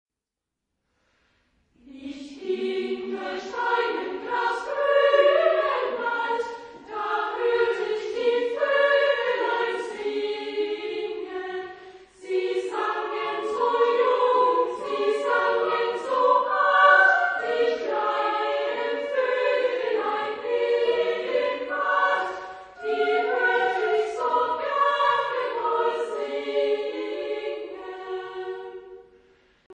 Genre-Style-Forme : Chanson ; Folklore ; Profane
Type de choeur : SSA  (3 voix égales de femmes )
Solistes : Sopran (1)  (1 soliste(s))
Tonalité : fa majeur
Réf. discographique : 7. Deutscher Chorwettbewerb 2006 Kiel